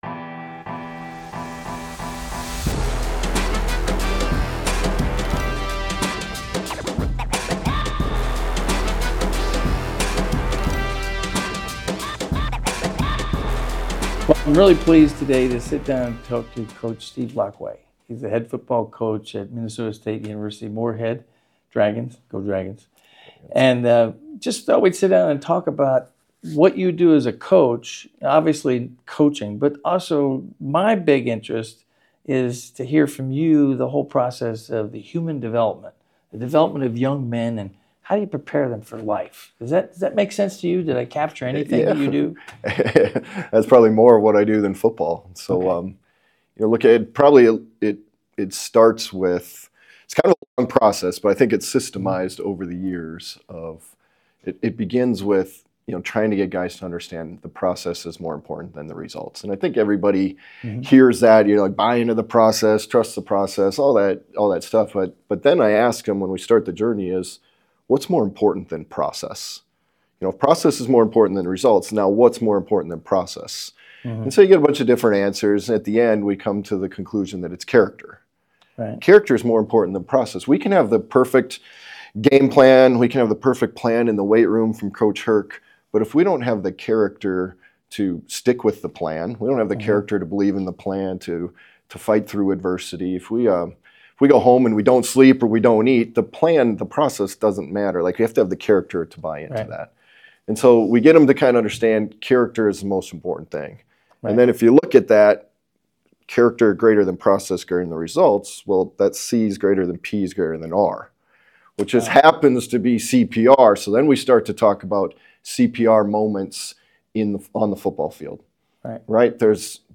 What happens when a football coach and a university president talk about culture, character, and human development....well...this. These two face off and interview each other about how their worlds overlap, differentiate, and both aim to create supportive and transformational experiences on the field and in the classroom.